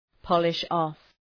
Προφορά
polish-off.mp3